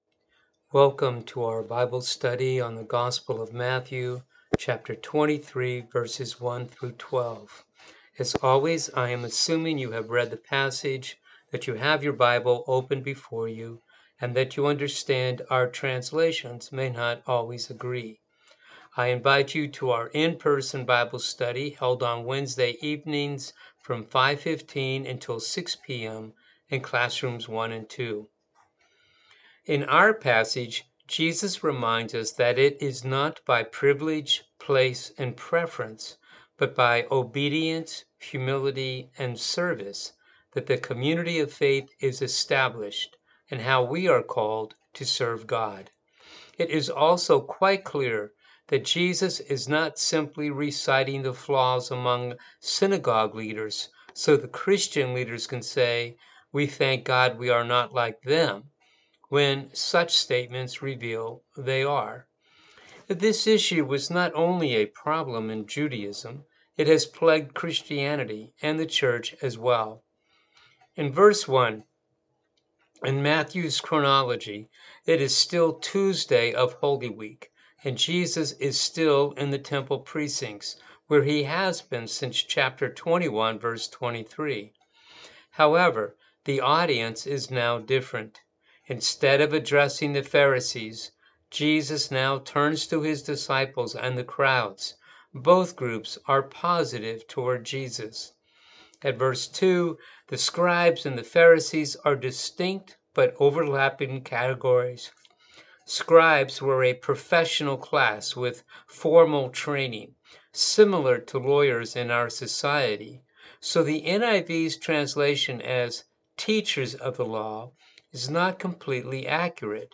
Bible Study for the November 5 service